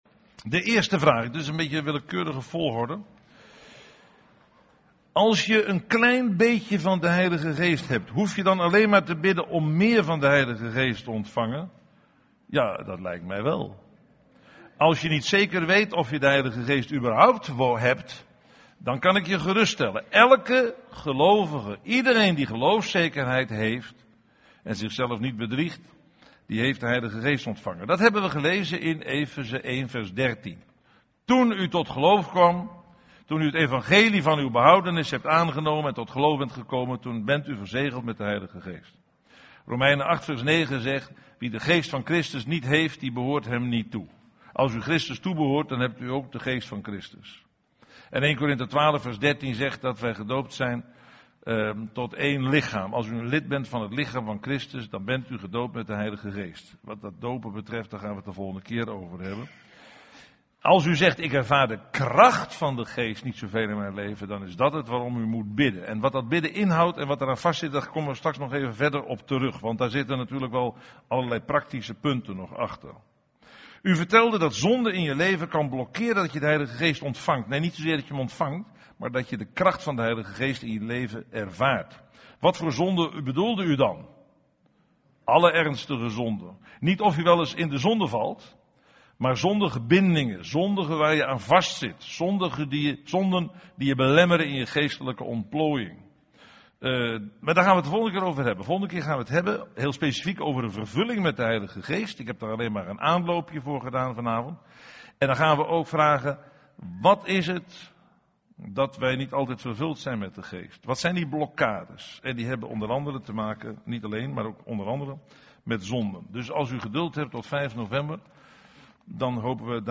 Vragenbespreking bij studie 1
vragenbespreking-zalving-en-verzegeling.mp3